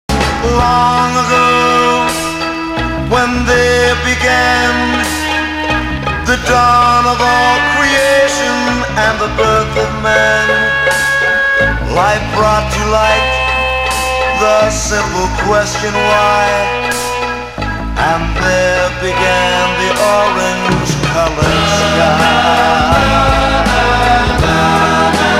トレードマークであるソフトなコーラスワークを活かした、サイケの香りもほのかにする極上のポップスに仕上がっている。
(税込￥1980)   SOFT PSYCH